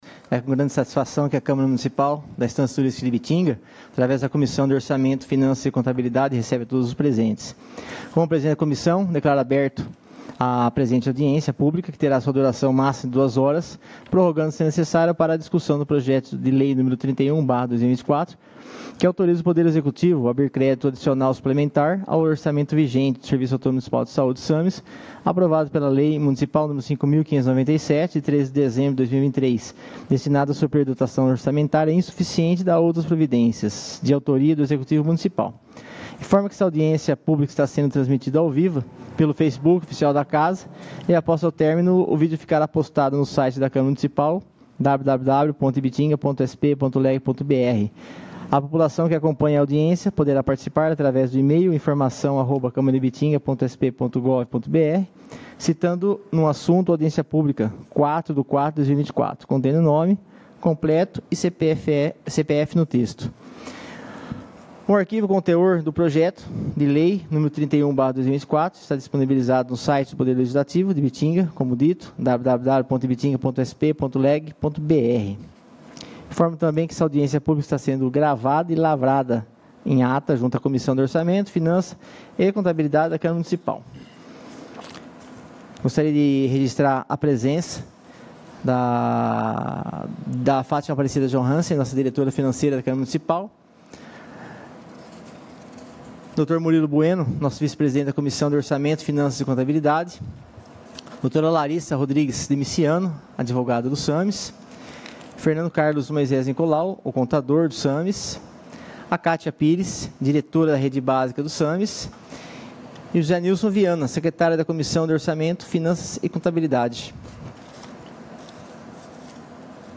04/04/2024 AUDIÊNCIA PÚBLICA DA COMISSÃO DE ORÇAMENTO, FINANÇAS E CONTABILIDADE, PARA APRESENTAÇÃO PÚBLICA DO PROJETO DE LEI Nº 31/2024, O QUAL AUTORIZA O PODER EXECUTIVO A ABRIR CRÉDITO ADICIONAL SUPLEMENTAR AO ORÇAMENTO VIGENTE DO SERVIÇO AUTÔNOMO MUNICIPAL DE SAÚDE - SAMS, APROVADO PELA LEI MUNICIPAL Nº 5.597, DE 13 DE DEZEMBRO DE 2023, DESTINADO A SUPRIR DOTAÇÃO ORÇAMENTÁRIA INSUFICIENTE, E DÁ OUTRAS PROVIDÊNCIAS.